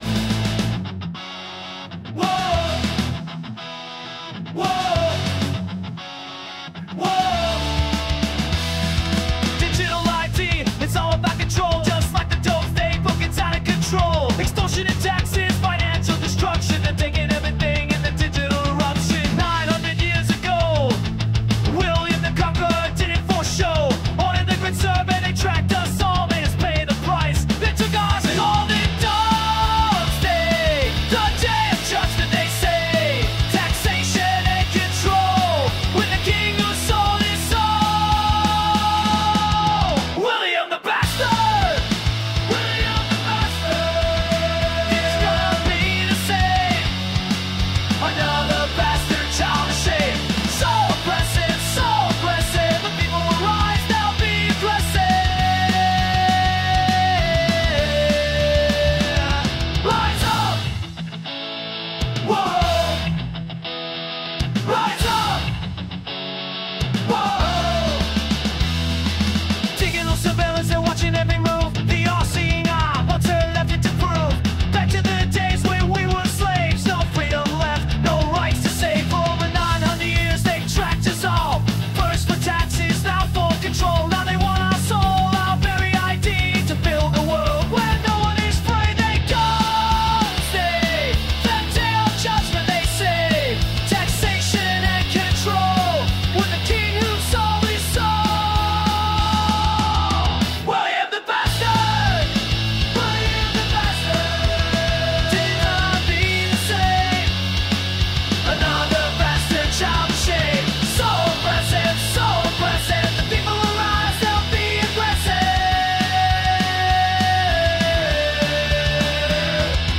PUNK ROCK VERSION